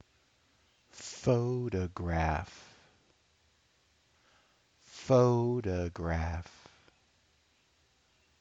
PHO-to-graph
[primary stress] + [unstressed] + [secondary stress]